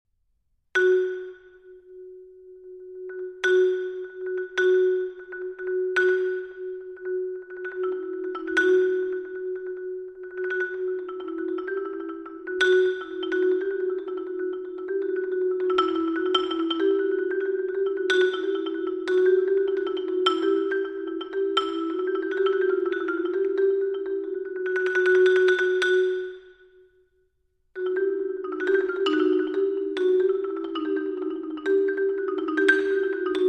for solo marimba